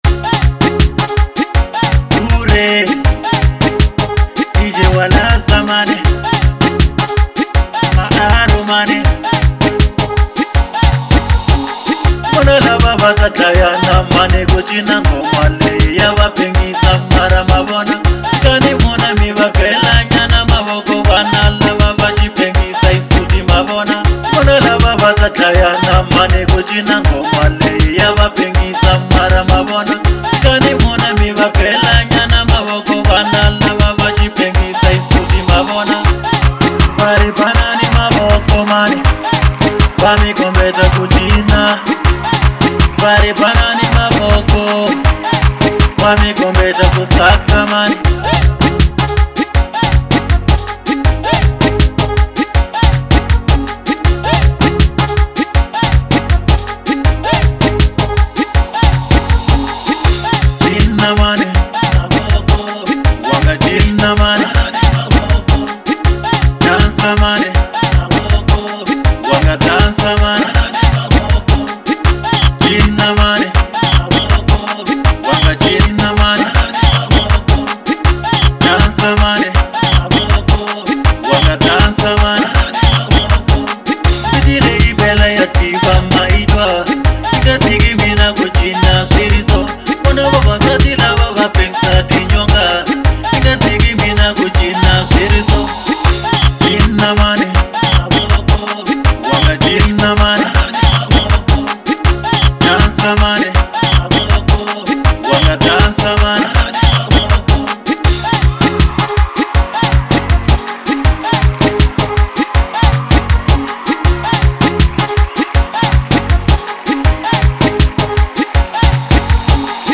04:21 Genre : Xitsonga Size